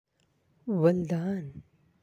(Waldan)
waldan.aac